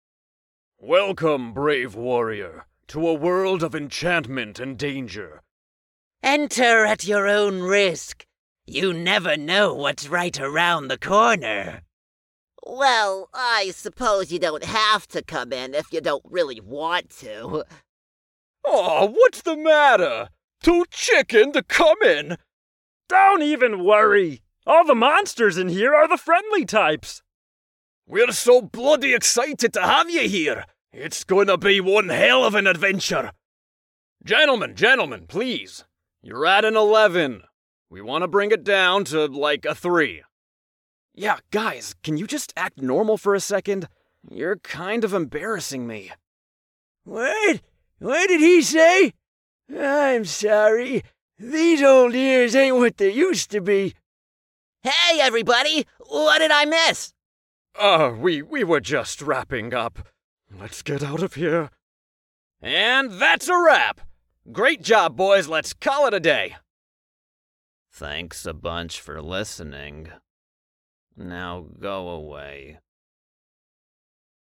Animation
J'utilise un microphone RDE NT1 avec un filtre anti-pop et une interface Focusrite Scarlett Solo dans une cabine vocale Voctent insonorisée et Audacity pour l'enregistrement et le montage.